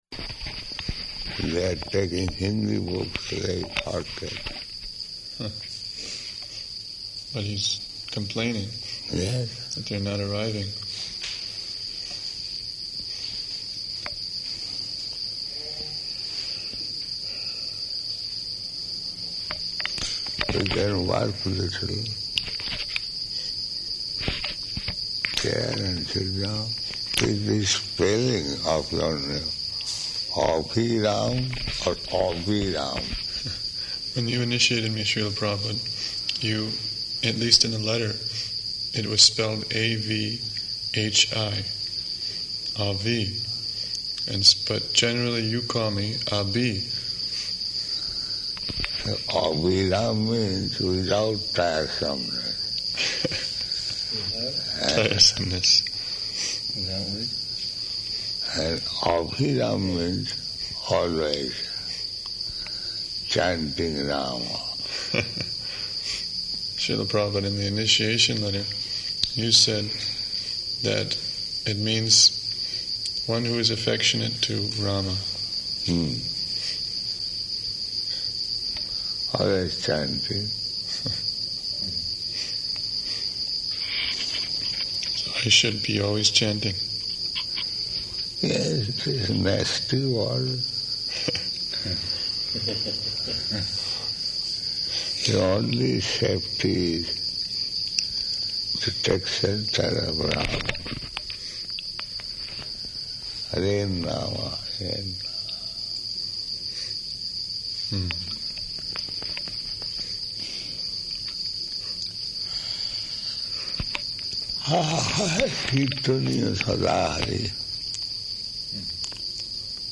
Room Conversation About 10th Canto